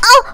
Worms speechbanks
ow1.wav